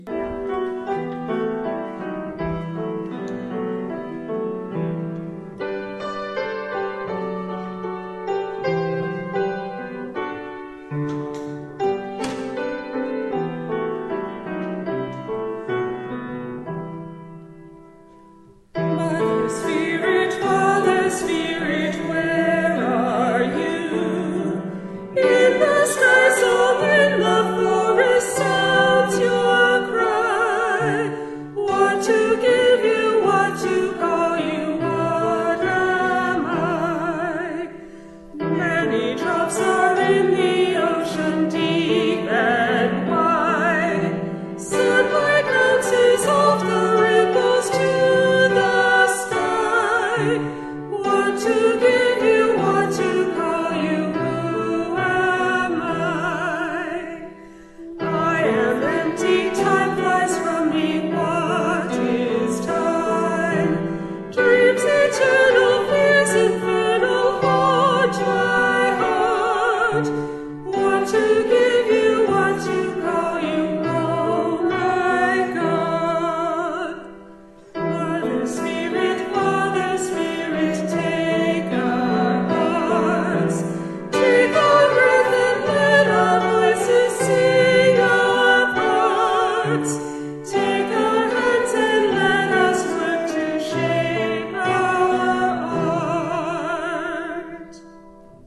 Prelude Mother Spirit Father Spirit
Norbert Čapek, sung by Waco UUC